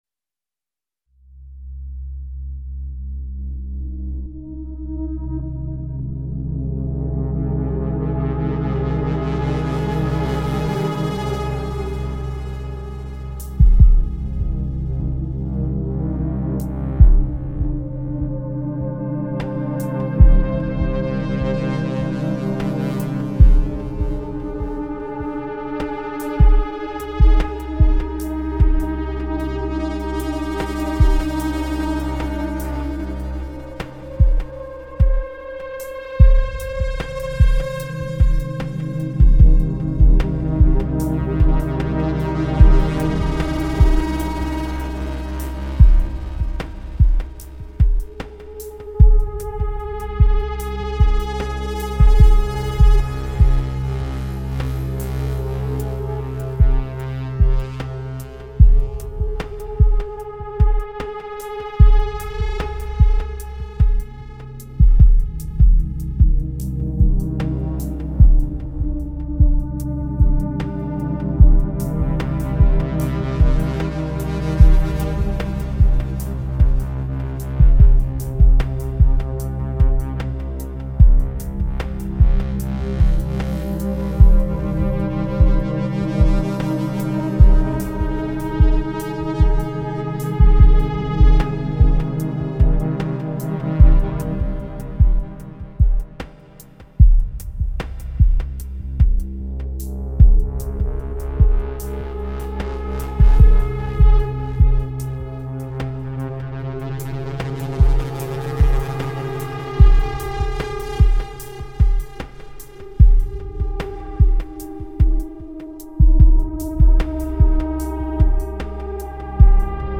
Digitone (DN+DT) only music
Random pad
T1 Drums
T2 Pad